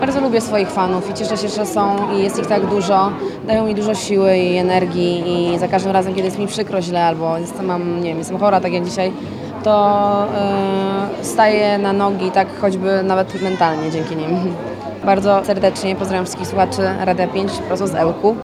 -Fani są dla mnie bardzo ważni- powiedziała w rozmowie z Radiem 5 Doda.